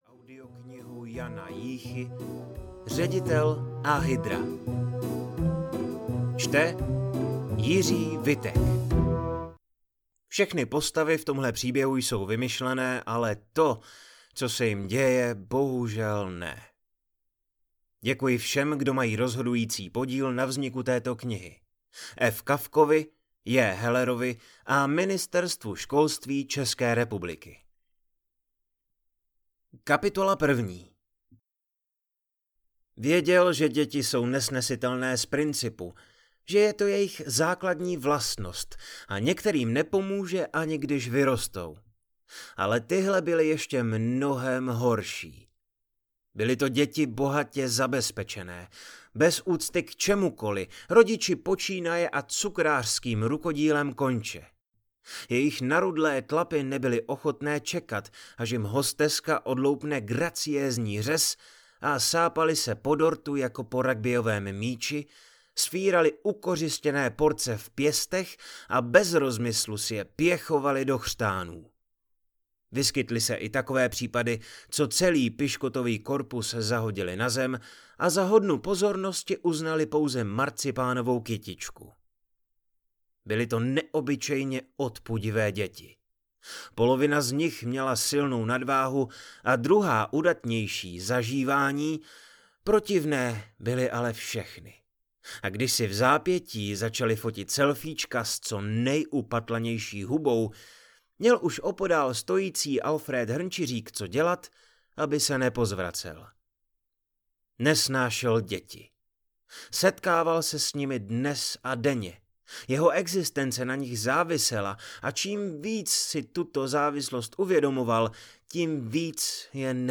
Ředitel a hydra audiokniha
Ukázka z knihy